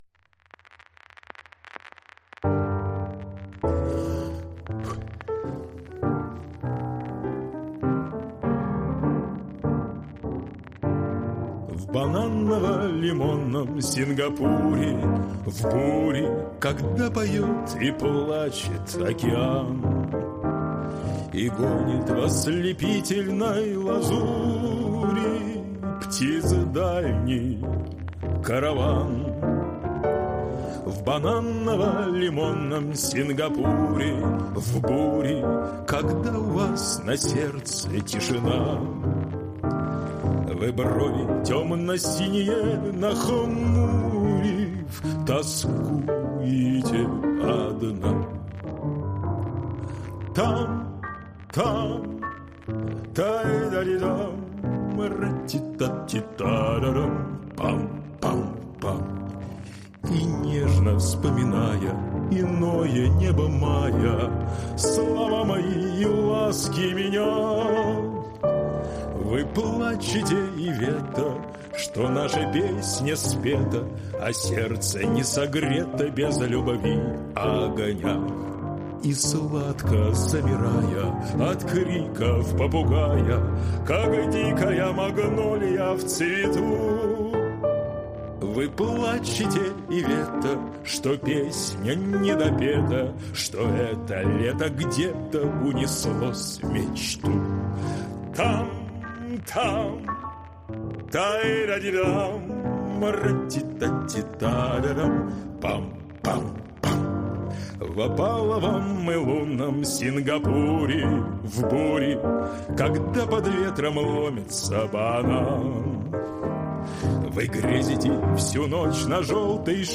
Тракт: RME BABYFACE PRO, микрофон СОЮЗ MALFA.